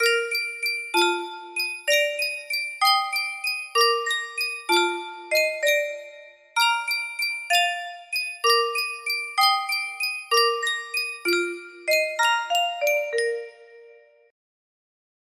Yunsheng Music Box - Drink to Me With Thine Eyes 6216 music box melody
Full range 60